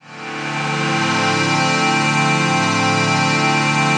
CHRDPAD003-LR.wav